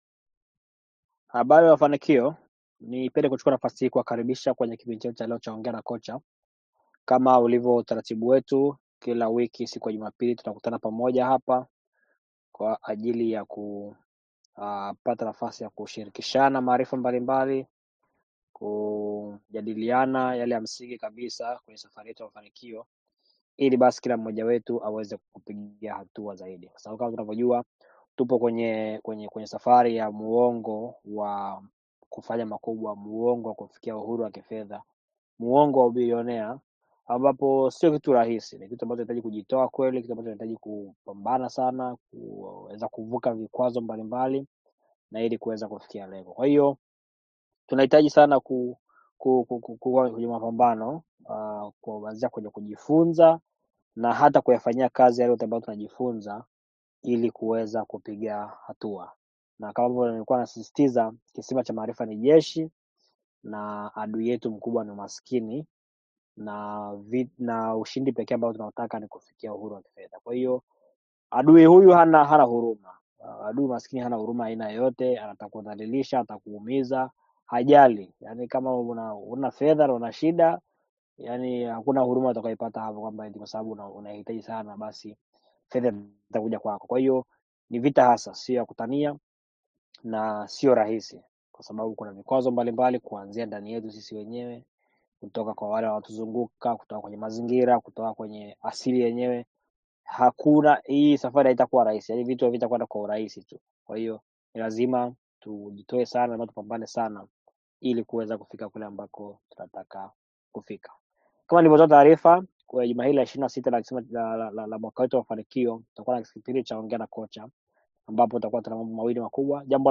Kwenye kipindi tumejadiliana kuhusu haya ambapo wanamafanikio mbalimbali wameshirikisha yale waliyojifunza pamoja na uzoefu wao.